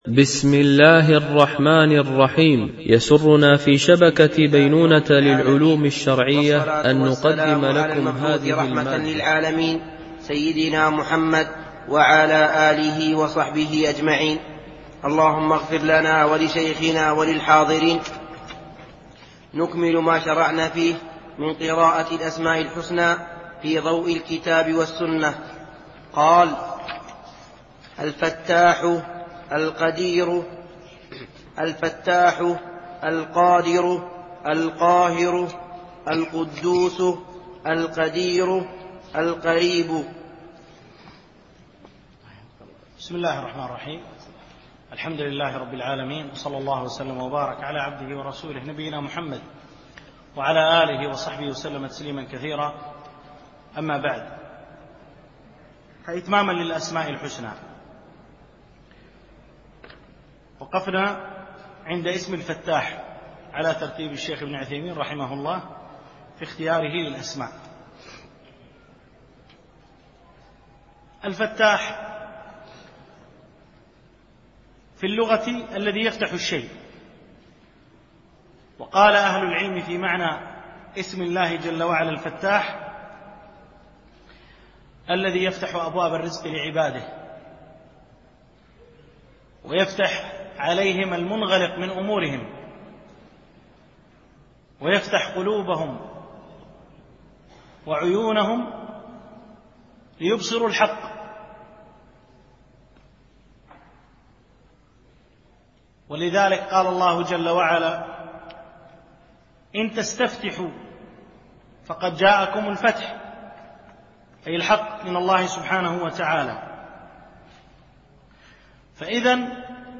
دورة الإمام مالك العلمية الرابعة، بدبي